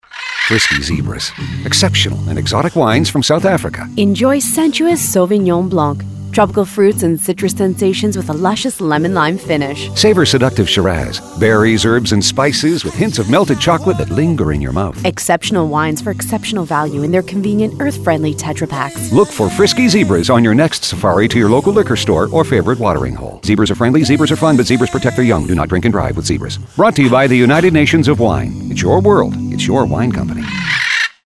Frisky Zebras Radio Commercial